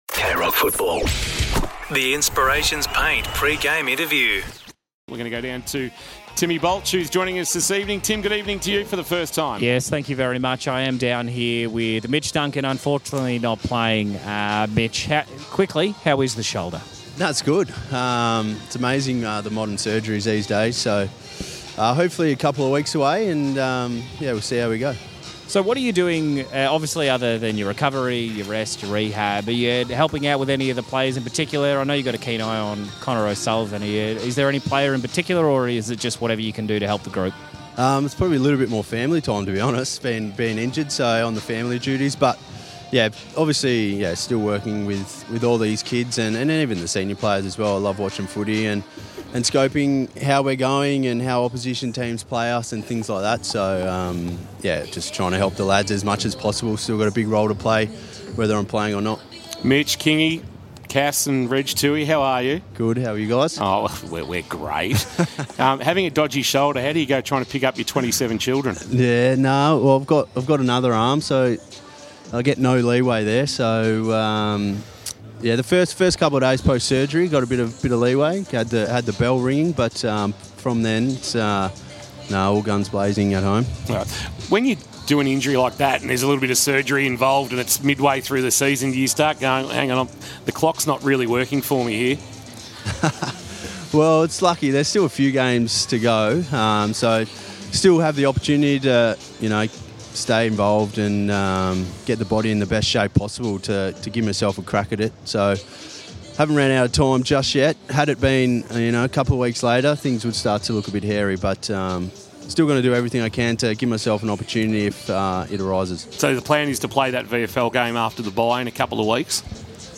2025 - AFL - Round 20 - North Melbourne vs. Geelong: Pre-match interview - Mitch Duncan (Geelong)